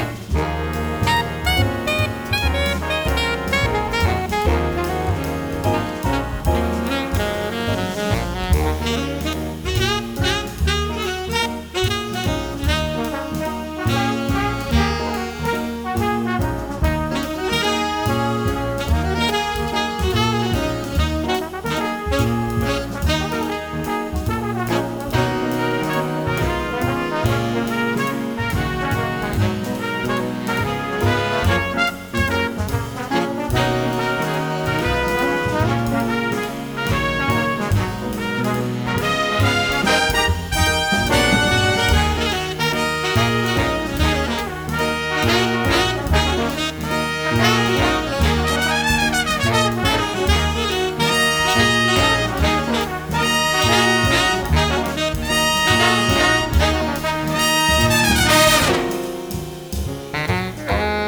The Best In British Jazz